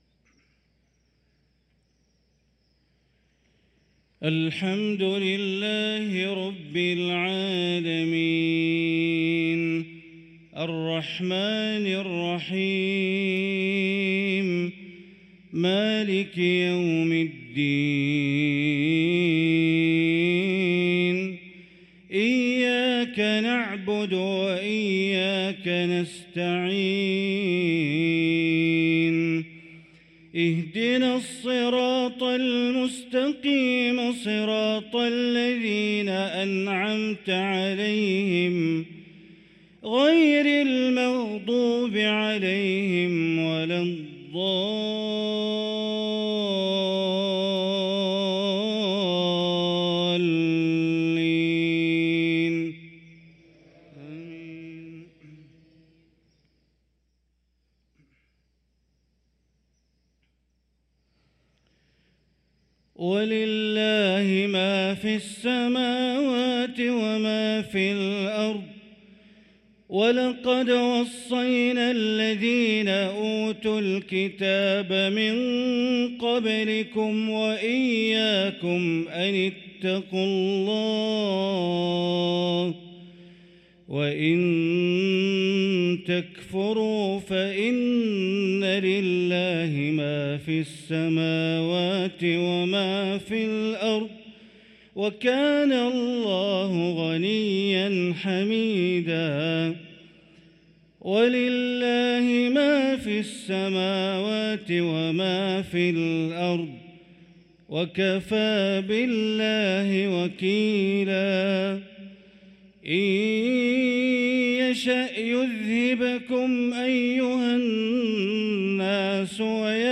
صلاة المغرب للقارئ بندر بليلة 28 ربيع الآخر 1445 هـ
تِلَاوَات الْحَرَمَيْن .